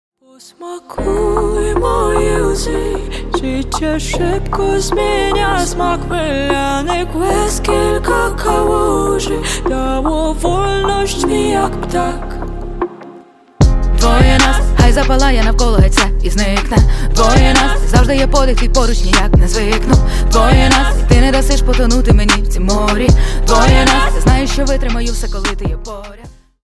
Рэп Хип-Хоп